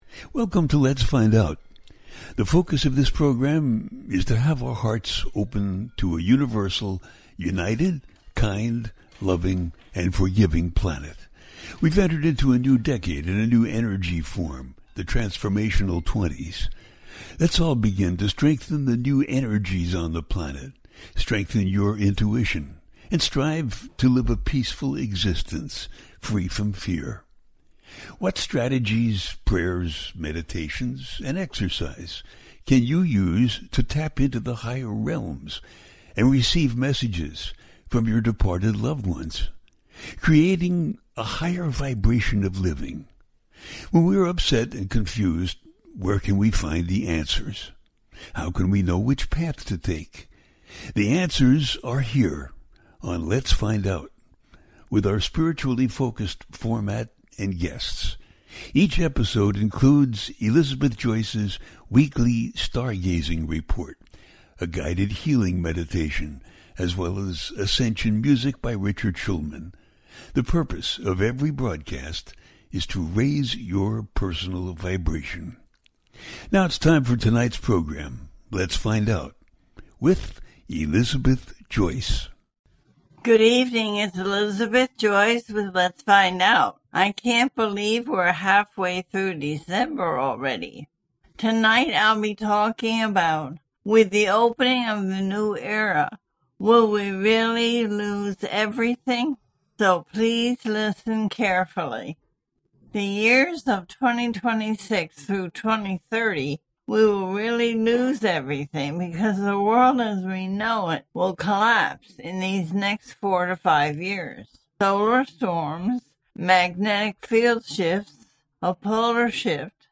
With the opening of the New Era - Will we really lose everything - 3i Atlas Update - A teaching show
The listener can call in to ask a question on the air.
Each show ends with a guided meditation.